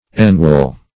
enwall - definition of enwall - synonyms, pronunciation, spelling from Free Dictionary Search Result for " enwall" : The Collaborative International Dictionary of English v.0.48: Enwall \En*wall"\, v. t. See Inwall .
enwall.mp3